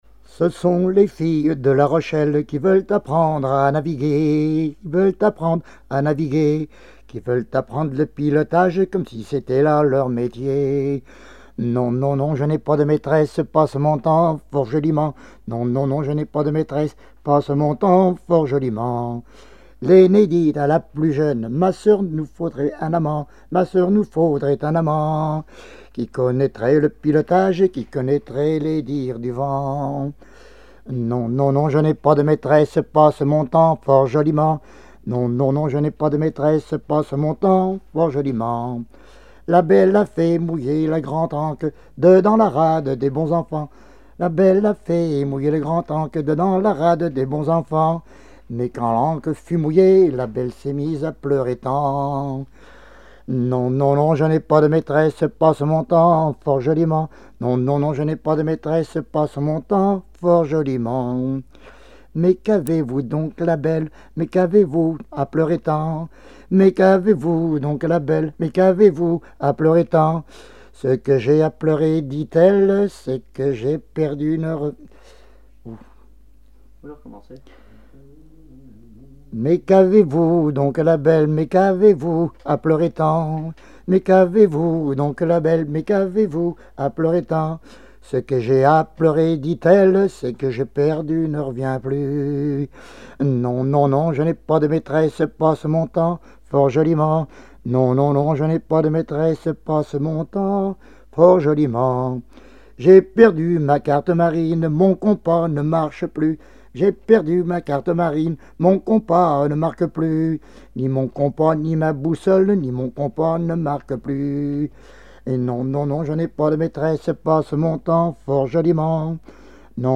Mémoires et Patrimoines vivants - RaddO est une base de données d'archives iconographiques et sonores.
Genre strophique
Catégorie Pièce musicale inédite